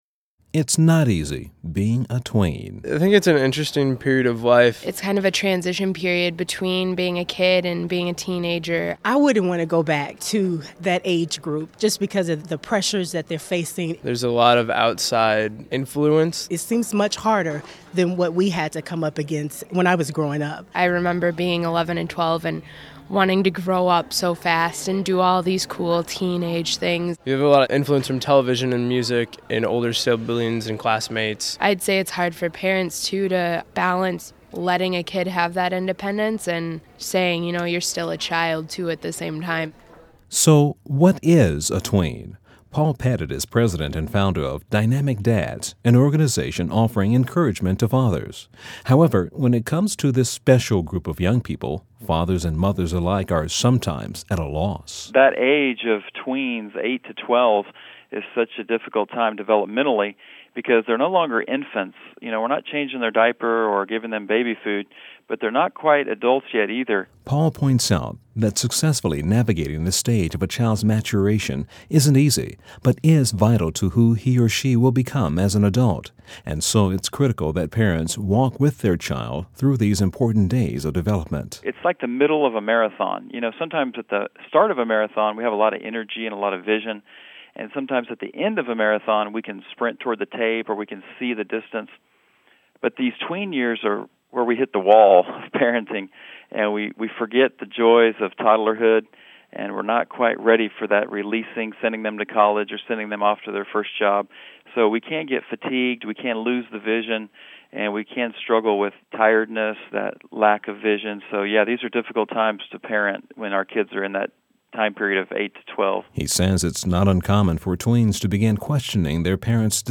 Dynamic Dads on Prime Time America - Moody Radio